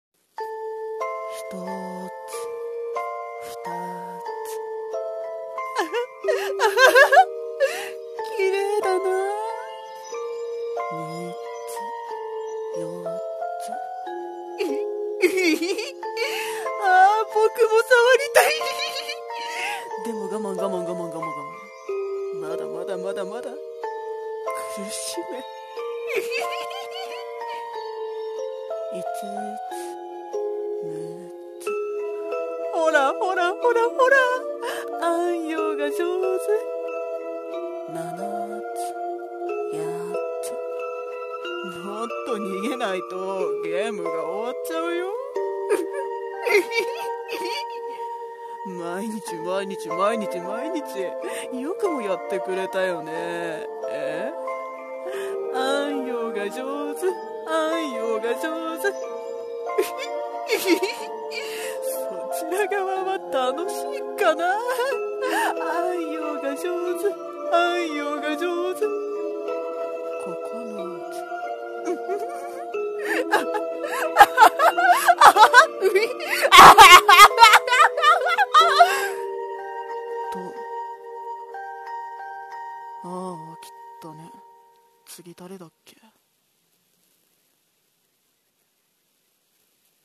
【声劇】フクシュウ【演技力/狂気】